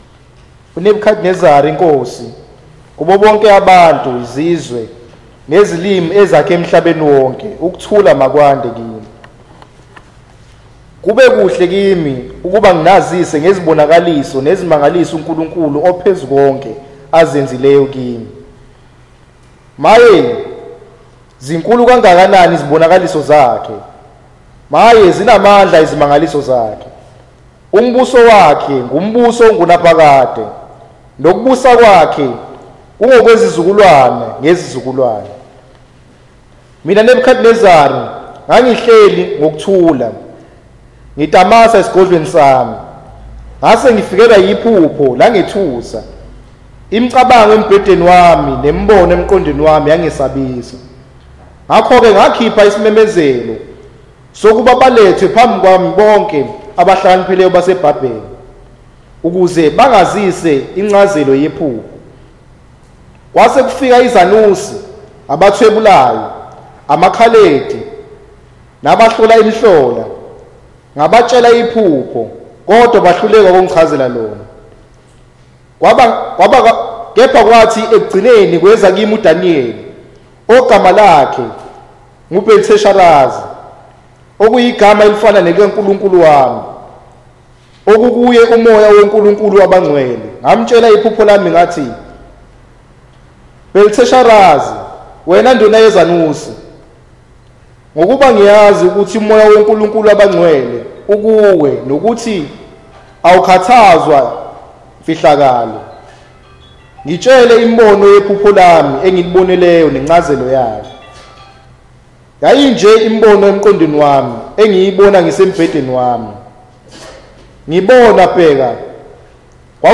Passage: UDaniyeli 4:1-37 Event: Zulu Sermon